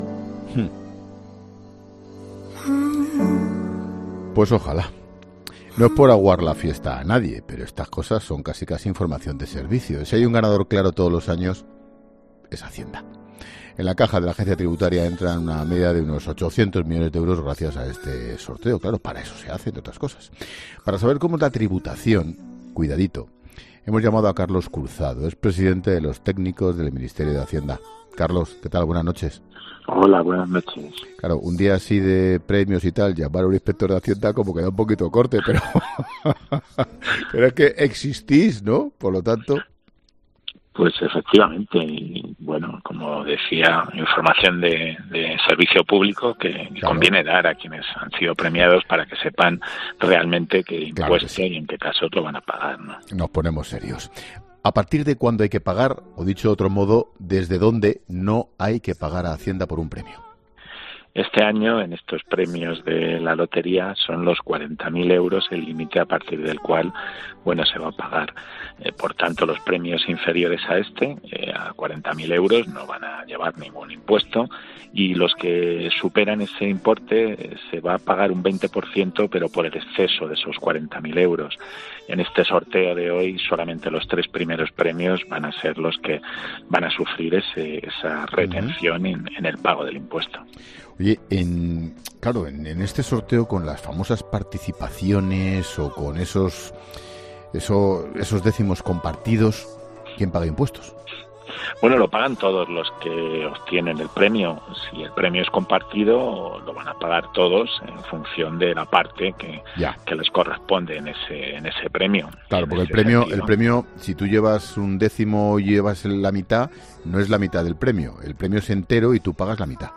Ángel Expósito enciende cada día La Linterna de 19 a 23.30 horas.